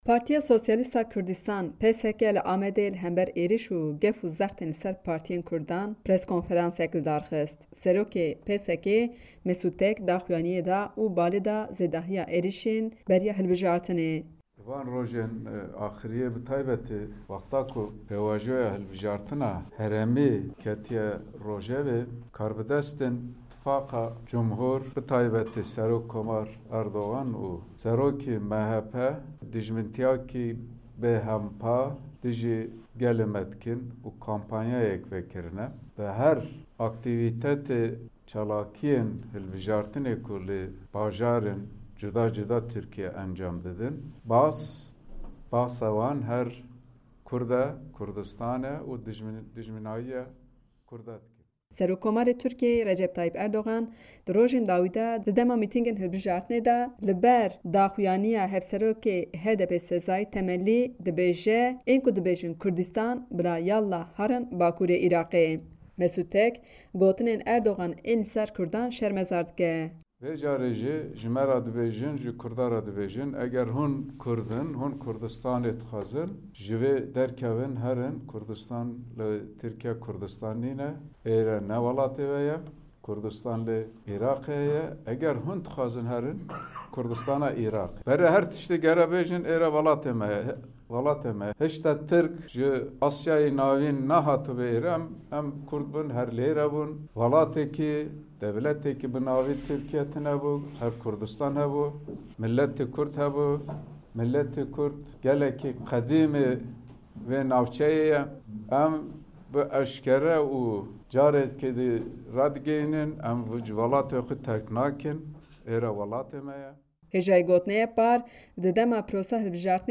Partiya Sosyalîsta Kurdistan (PSK) li Amedê li hember êrîş, gef û zextên li ser partiya xwe preskonferansek lidarxist.